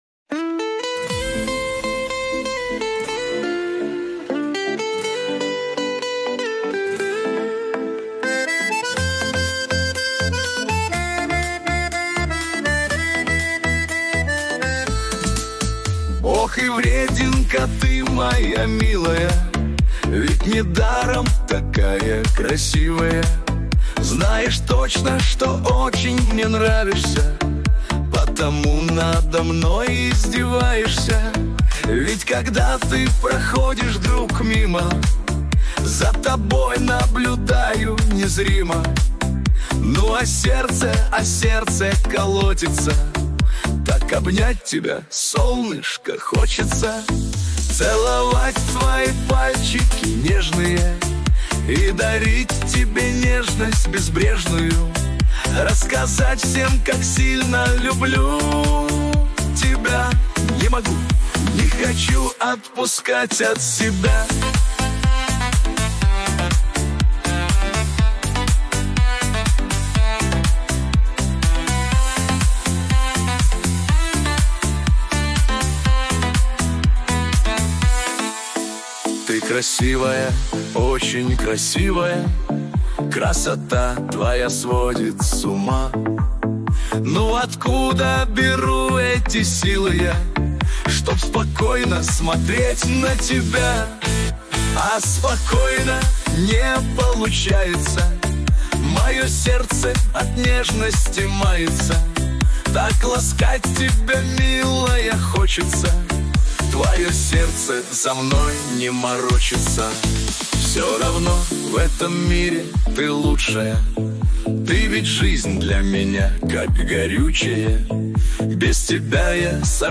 Жанр: Мужской вокал